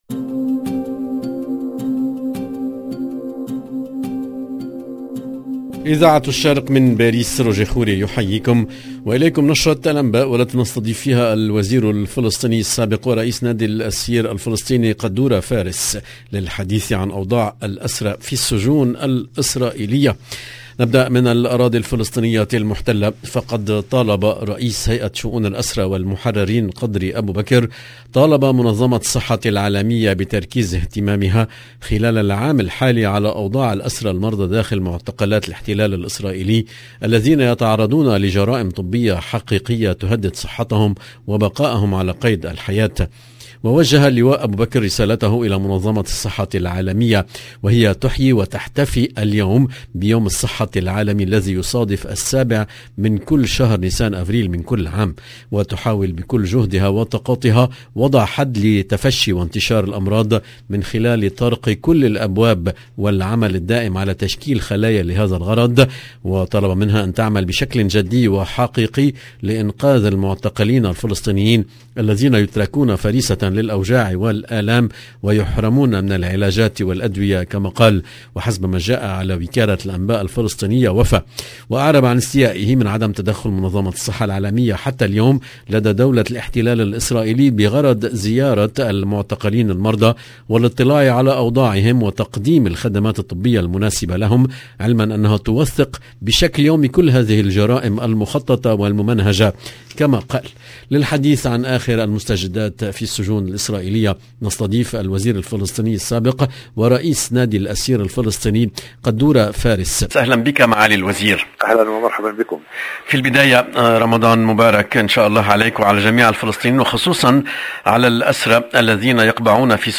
LE JOURNAL DU SOIR EN LANGUE ARABE DU 7/4/2022